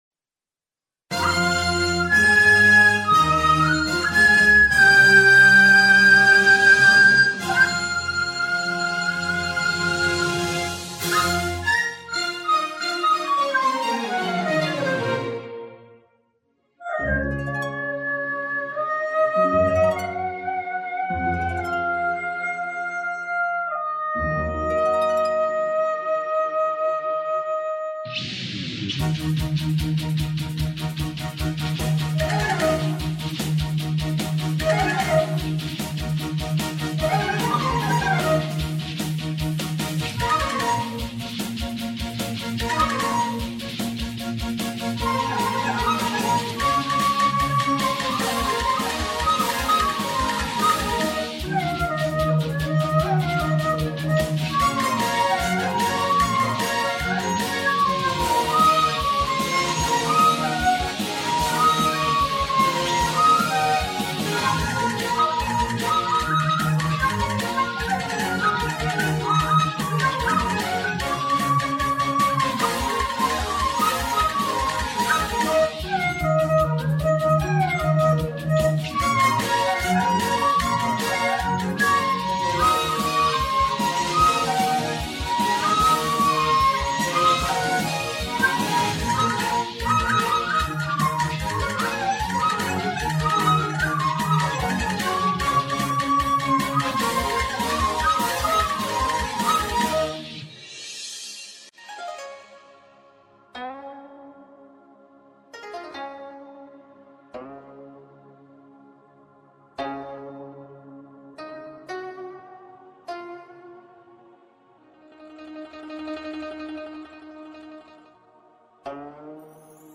秦腔堪称百戏之祖，旋律节奏鲜明多样，唱腔质朴豪爽，既高亢悲壮，激越人心，又委婉优美，细腻抒情，地方色彩风格浓郁，极富感染力。
该曲完整版还要再加上的前、后两部分高频快板，情绪激昂豪放、节奏铿锵火爆，乡土朴实的性格体现的淋漓尽致，有先声夺人之感。快板吹奏难度颇大，有相当的挑战性。
第一次听到笛子演绎秦腔，精彩！
这笛子声让人陶醉，悠扬激越，荡气回肠！超赞